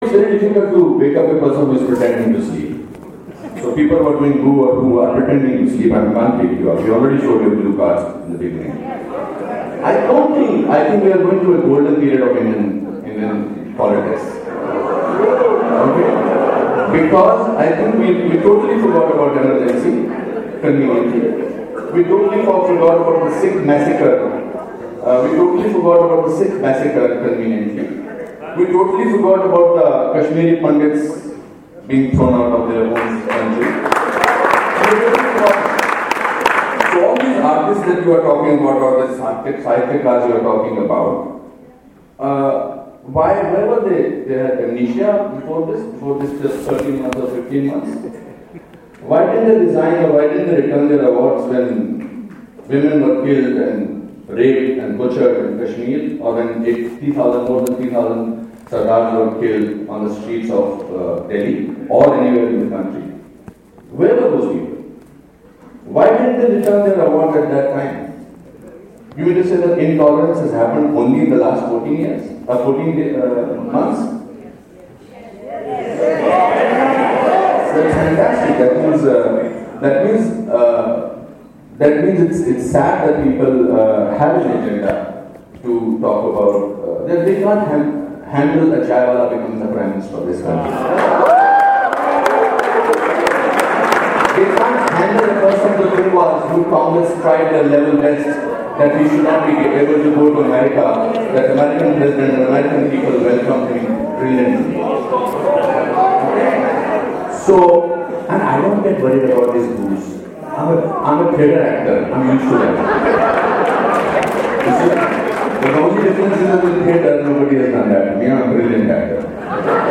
Tata LitLive full debate: It was at a debate on 'Freedom of expression is in imminent danger,' where actor Anupam Kher and Nalin Kohli of the BJP were speaking against the motion, facing off with celebrated writers Shobhaa De and Sudheendra Kulkarni who spoke for it.